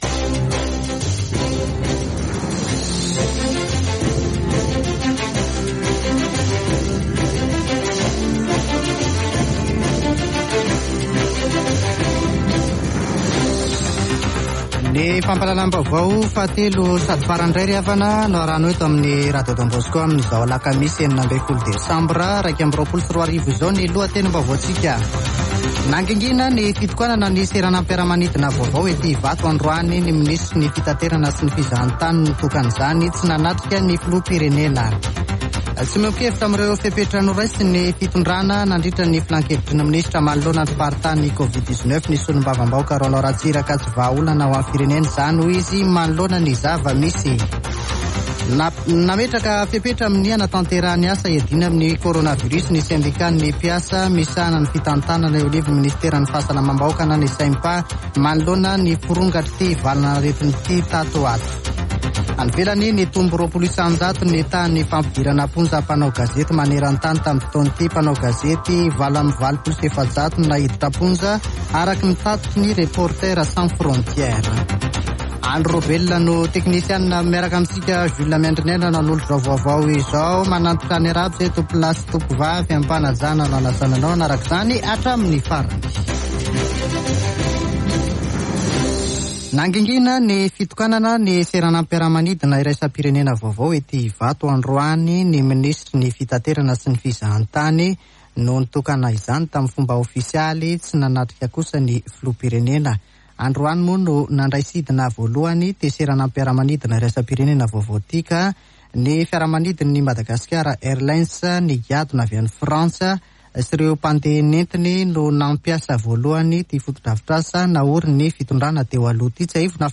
[Vaovao hariva] Alakamisy 16 desambra 2021